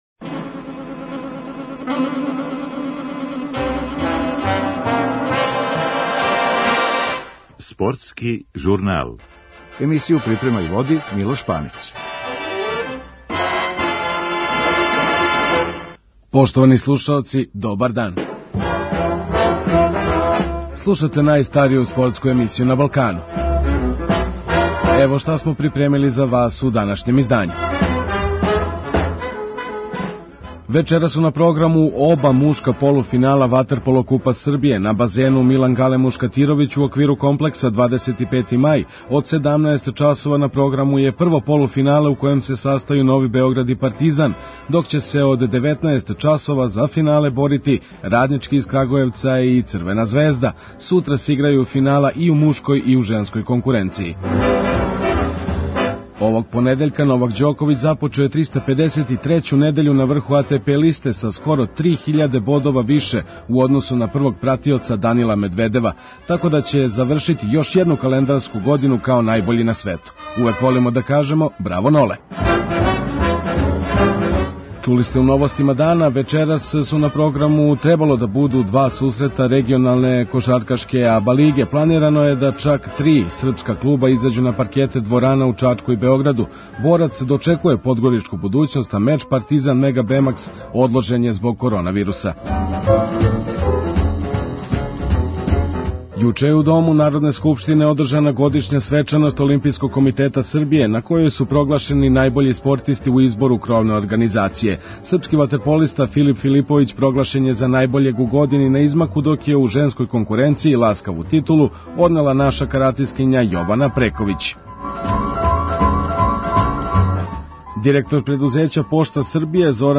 У данашњој емисији чућете њихове изјаве.
За нашу емисију говорили су аутори овог вредног дела, које ће остати наредним генерацијама као историјско сведочење.